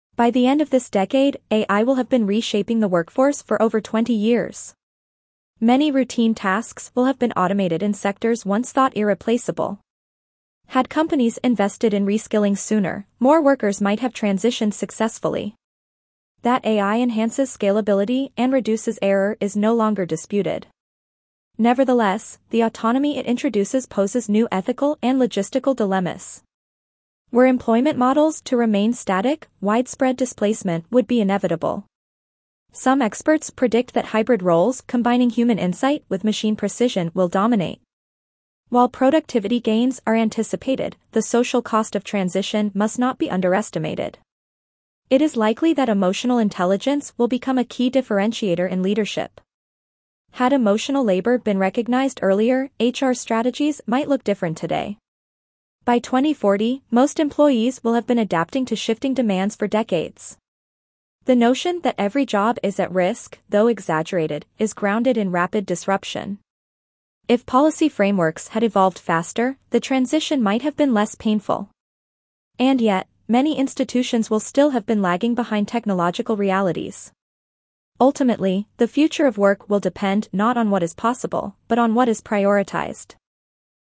C2 Dictation – The Future of Work and AI